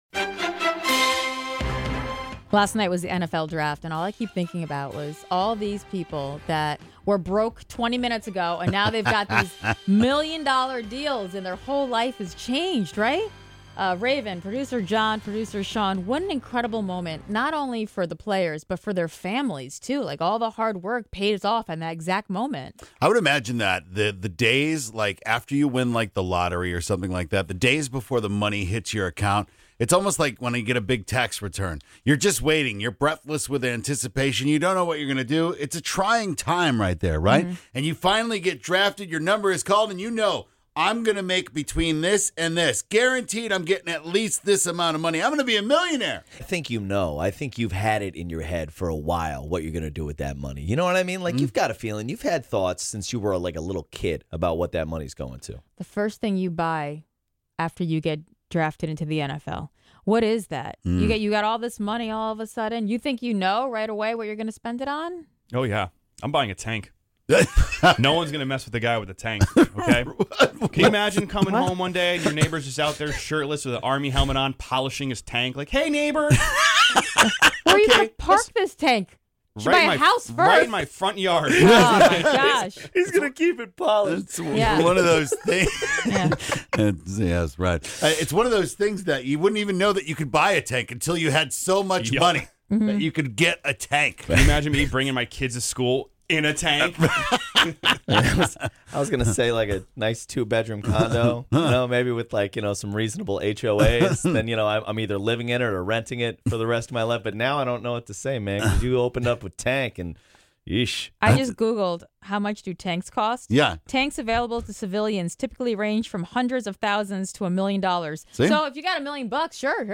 Moms (and Dads) share all the crazy things that their kids have put them through this week that have definitely earned them a margarita!